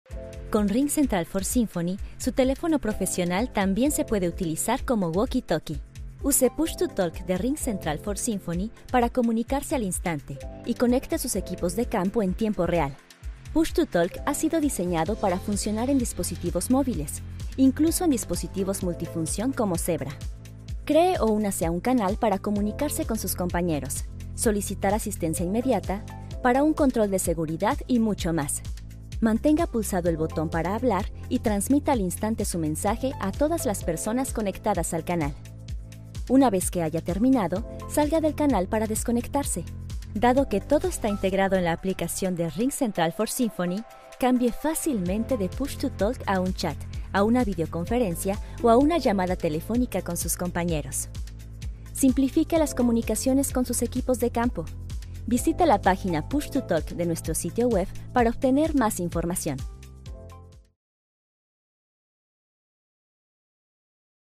Spanish (Latin-America)
Young, Natural, Friendly, Soft, Corporate
Corporate